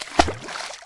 splash.mp3